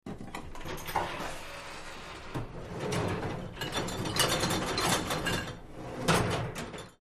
Dishwasher; Door 3; Door Opens, Dish Rail Is Pulled Out Causing Several Glass Movements. Close Perspective Kitchen, Restaurant.